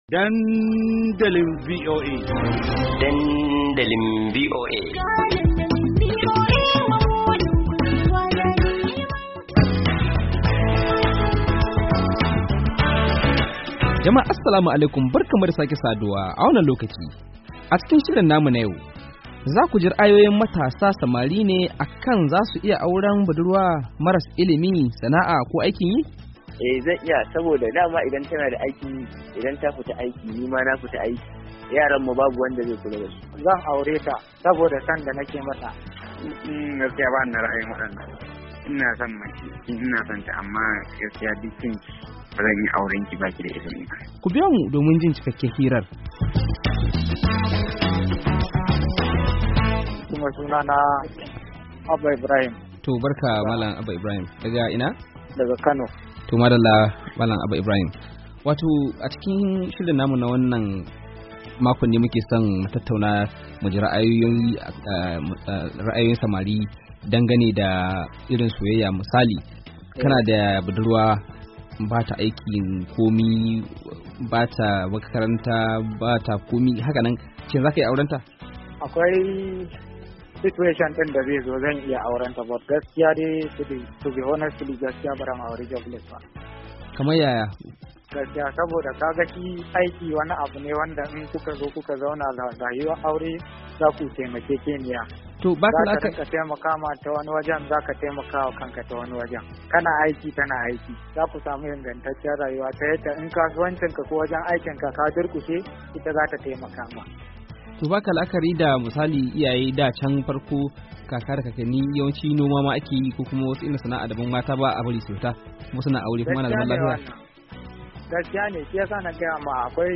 Kamar yadda muka saba a kowane karshen mako, shirin samartaka na dandalinvoa na kawo maku hira da matasa samari da ‘yan mata akan lamurra daban daban da suka shafi zamantakewa, soyayya, da sauran batutuwa makamantan haka, a wannan satin mun sami zantawa ne da matasan domin jin ra’ayoyinsu akan...